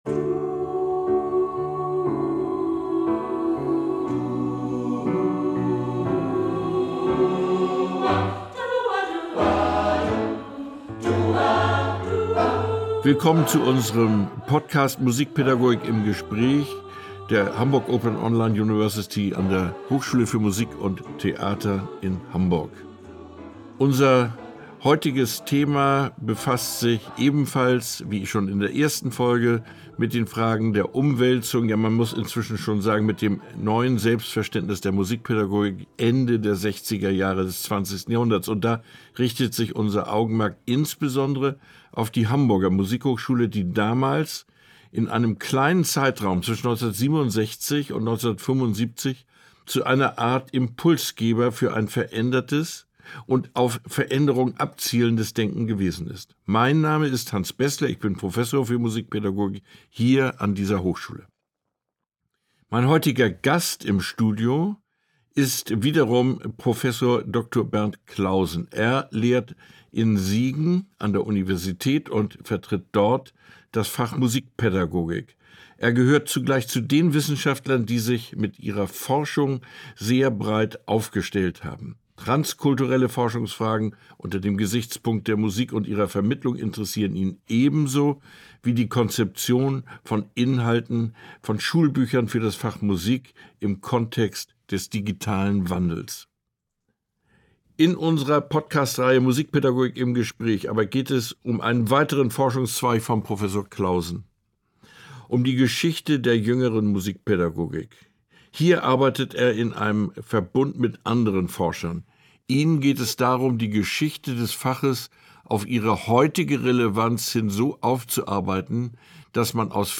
Kriterien für musikpädagogische Reformbestrebungen (nicht nur) in Hamburg ~ Musikpädagogik im Gespräch Podcast